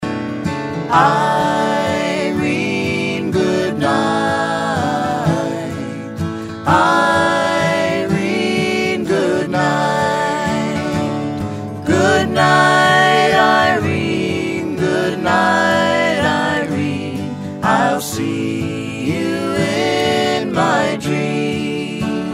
classic folk song favorites